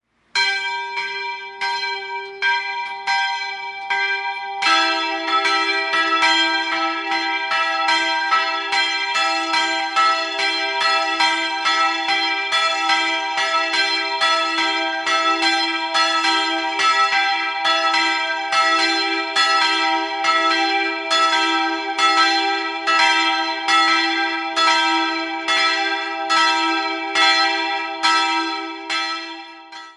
2-stimmiges Kleine-Terz-Geläute: e''-g'' Die Glocken wurden 1965 von Friedrich Wilhelm Schilling in Heidelberg gegossen.
Eichstaett_HeiligeFamilie.mp3